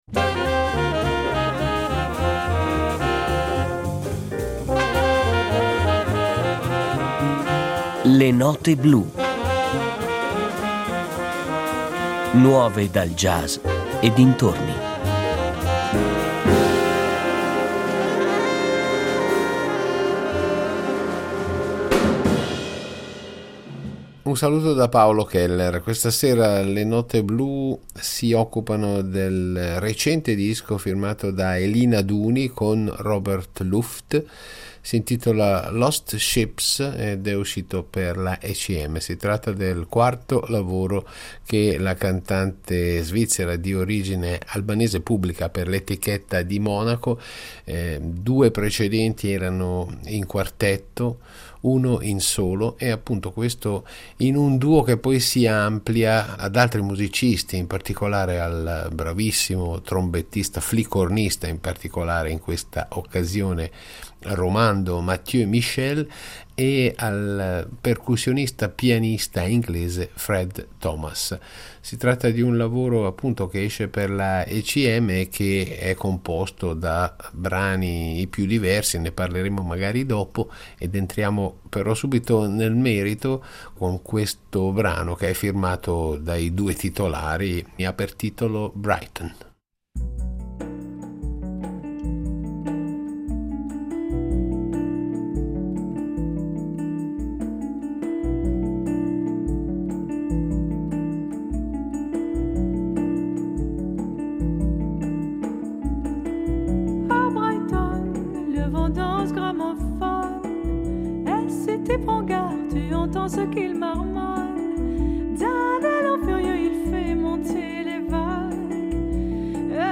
chitarrista
trombettista/flicornista
pianista/percussionista
Il mondo delle novità legate al jazz e dintorni